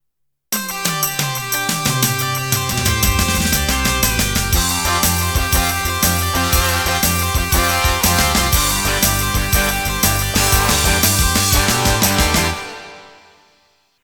triplet based rock shuffles